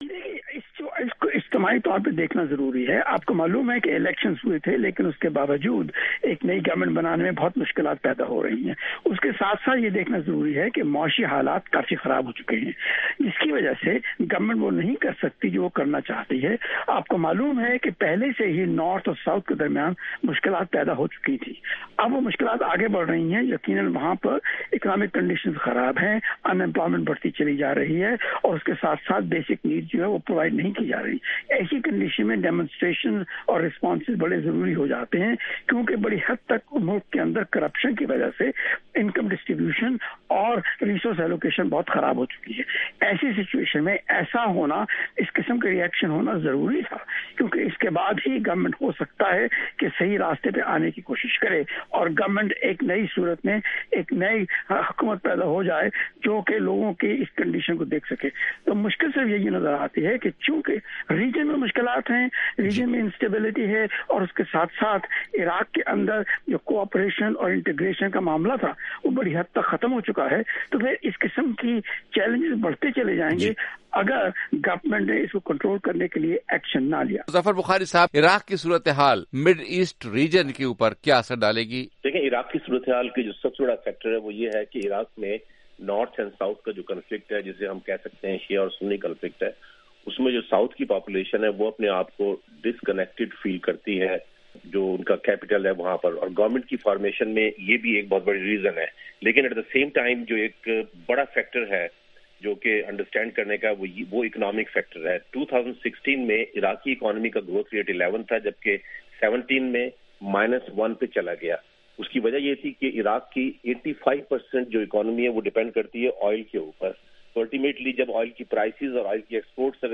انتخابات کے بعد عراق میں مظاہرے اور کرفیو کا نفاذ: 'جہاں رنگ' میں ماہرین کی گفتگو